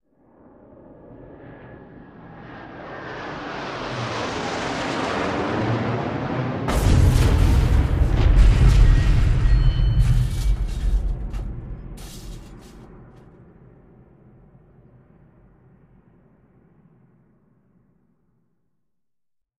Fighter jet drops exploding bombs. Incoming, Explosion War, Explosion Explode, Bomb